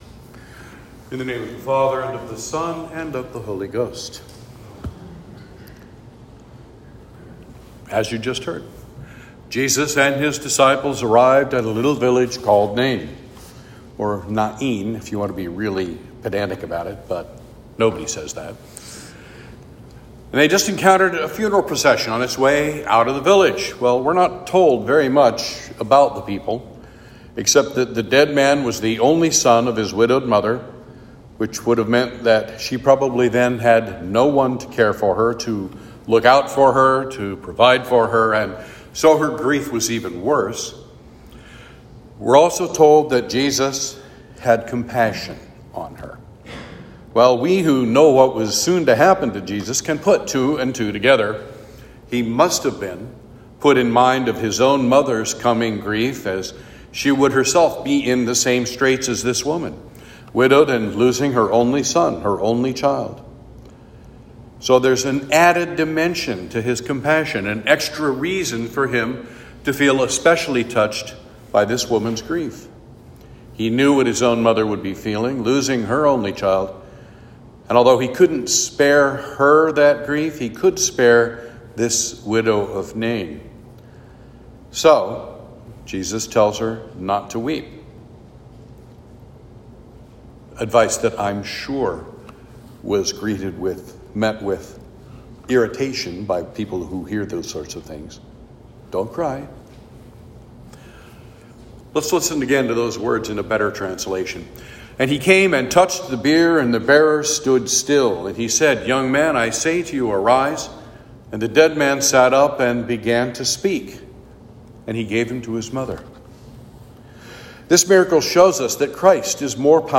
Sermon for Trinity 16